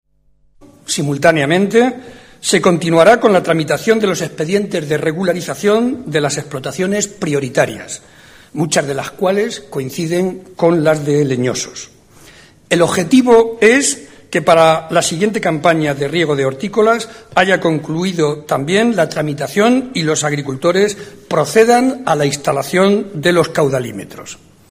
JCCM Jueves, 9 Julio 2009 - 2:00am El presidente de Castilla-La Mancha, José María Barreda, manifestó esta mañana en Tomelloso (Ciudad Real ) que se continuará con la tramitación de los expedientes de regularización de explotaciones prioriatrias, muchas de las cuales coiniciden con las de leñosos.